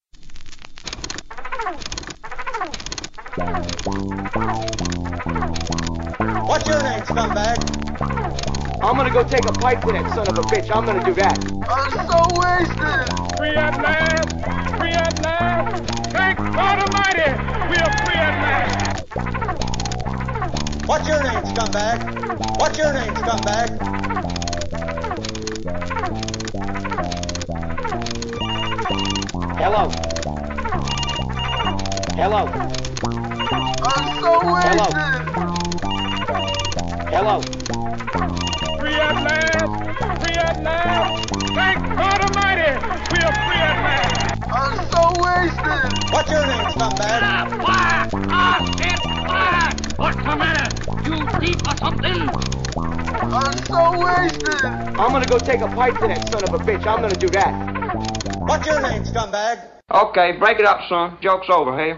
Tags: silly nutty weird noise sound collage